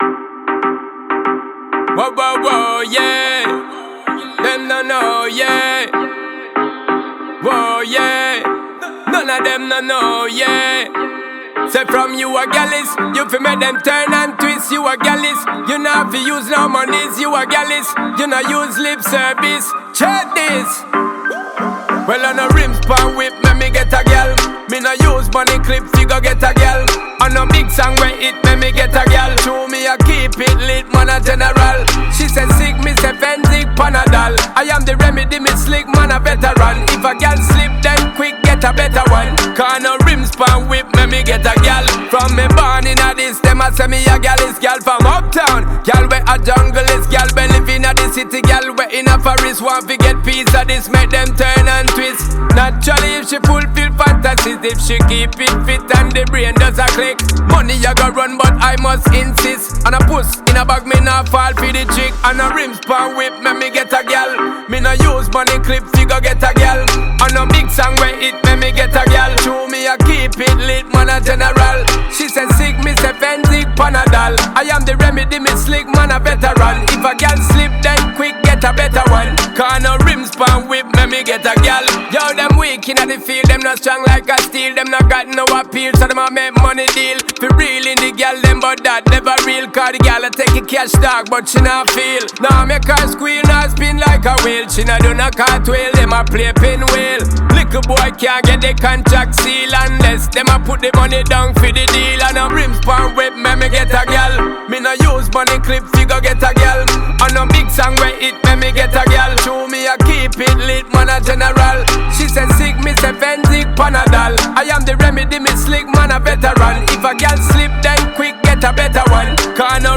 энергичная регги-рап композиция